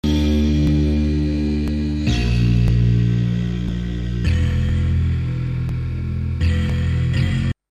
Ich habe schon alle möglichen Werte (v. a. die Puffergröße) verstellt, aber es bleibt bei einem rhythmischen Knacken von etwa einmal pro Sekunde.
Außerdem ein Audio-Mitschnitt von einem Musikbett, bei dem man das Knacken besonders deutlich hört.
knacken2.mp3 (121 KB)